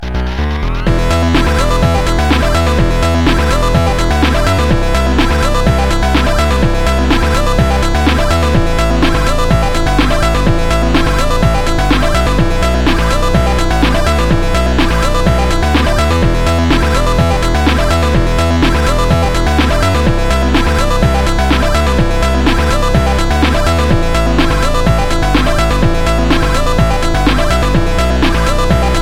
Kategori Ses Efektleri